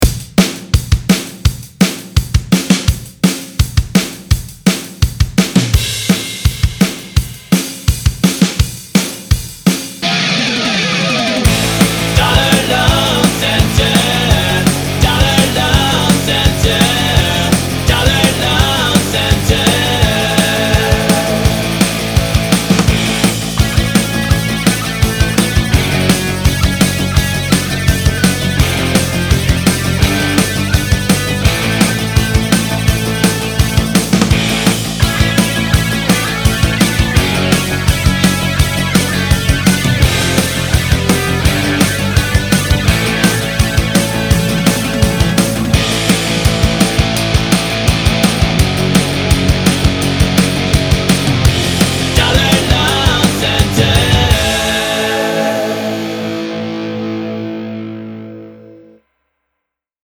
Custom Audio and Jingles